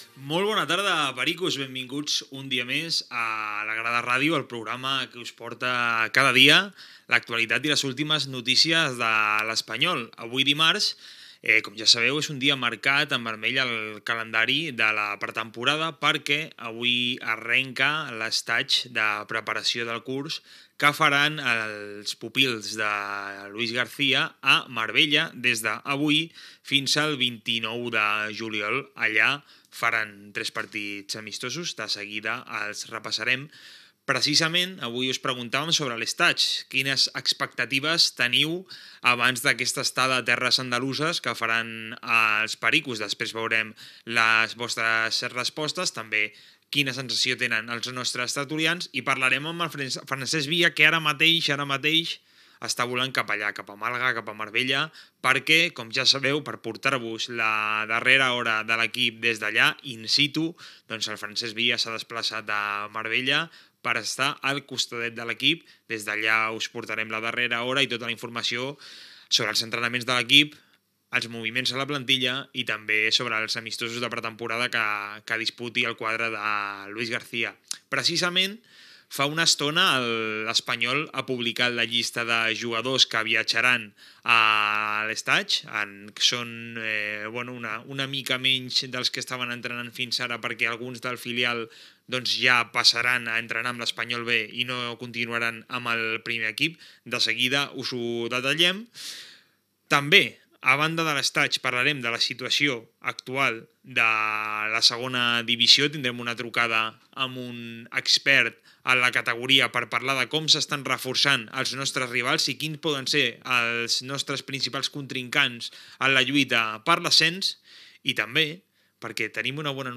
Esportiu
Presentador/a